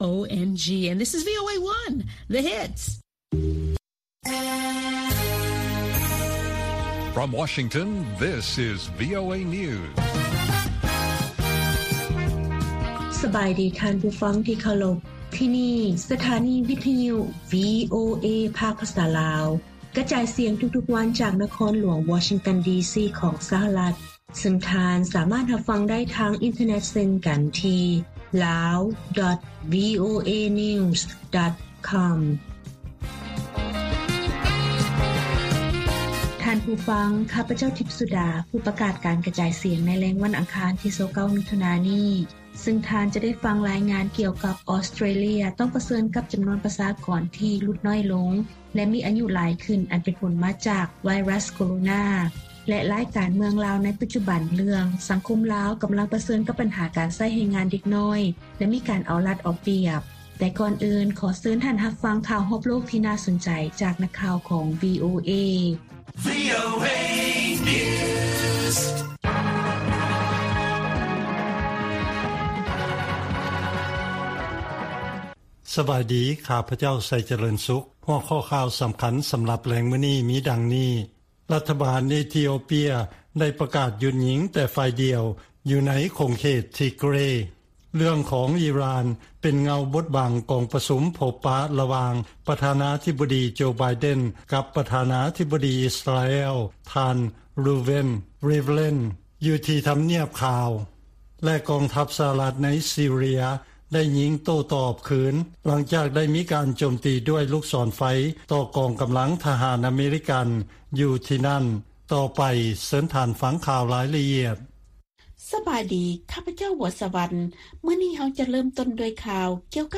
ວີໂອເອພາກພາສາລາວ ກະຈາຍສຽງທຸກໆວັນ. ຫົວຂໍ້ຂ່າວສໍາຄັນໃນມື້ນີ້ມີ: 1) ລັດຖະມົນຕີ ຂອງກຸ່ມຈີ 20 ຈະຫາລືກັນເລື້ອງໄວຣັສໂຄໂຣນາ, ການປ່ຽນແປງຂອງອາກາດ ແລະການພັດທະນາຢູ່ອາຟຣິກາ.